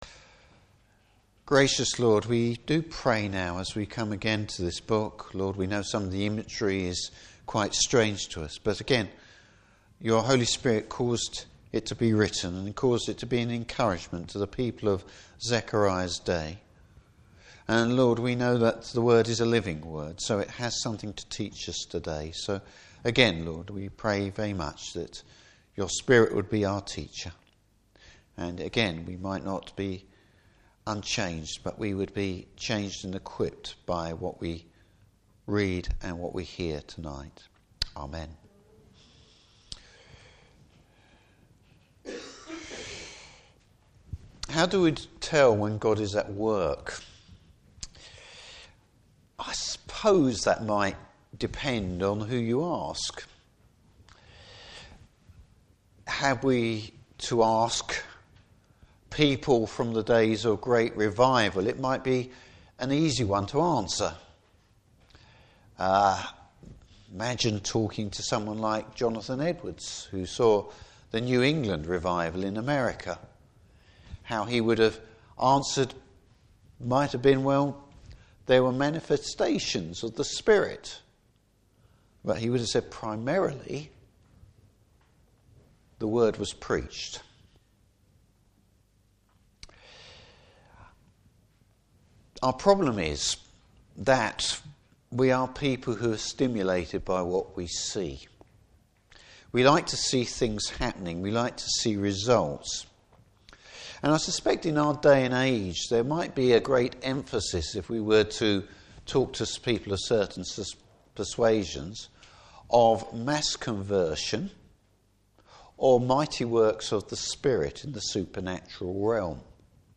Service Type: Evening Service The Holy Spirit is always at work!